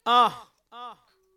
UH - daz.wav